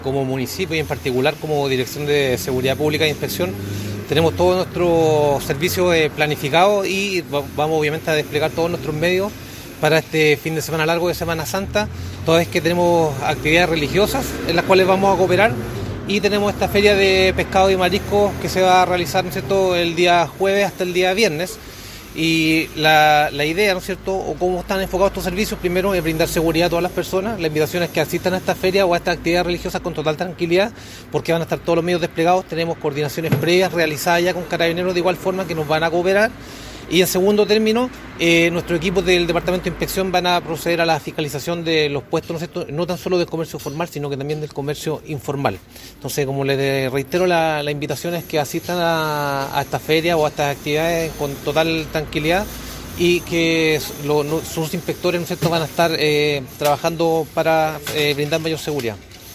se efectuó un punto de prensa en el frontis de Delegación Presidencial Provincial de Melipilla